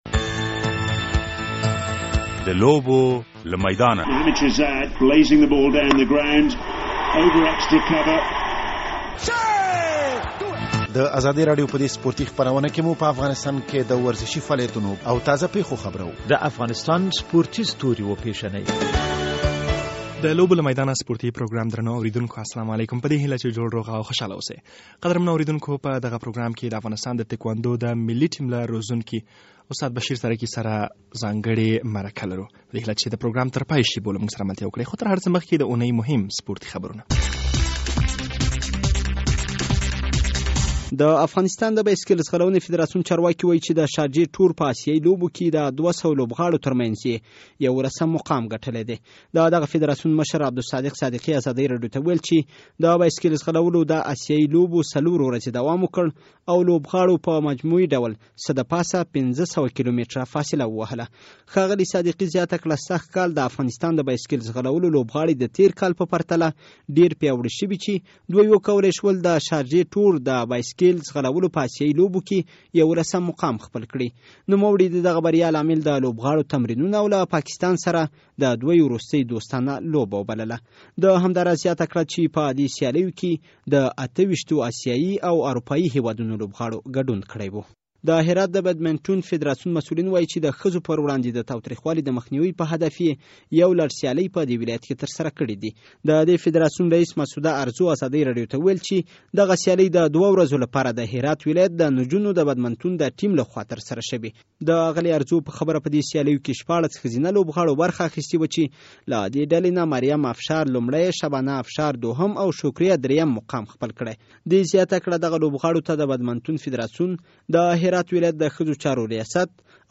د لوبوله میدانه سپورټي پروګرام خپریدو ته چمتو دی.